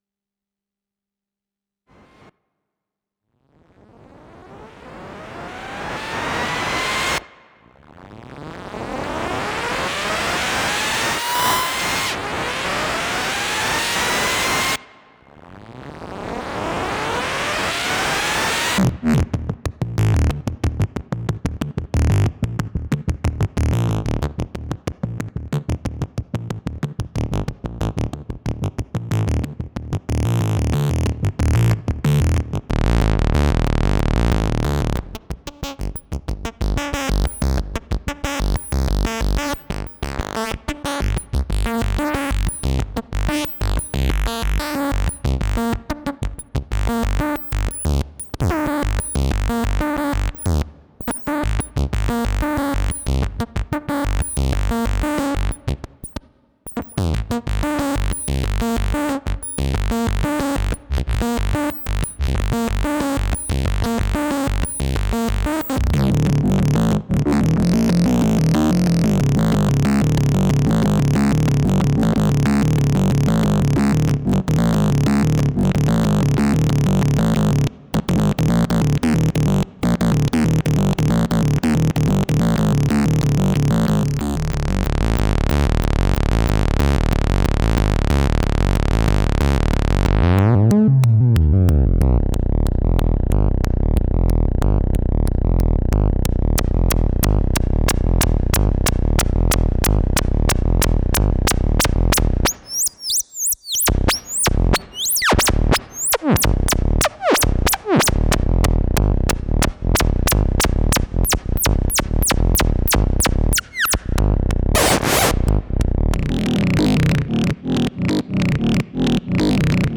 один патч, на скорую руку на 8 шагов ряд с коржика, 6 минут сплошного благозвучия чесслово